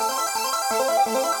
Index of /musicradar/shimmer-and-sparkle-samples/170bpm
SaS_Arp02_170-A.wav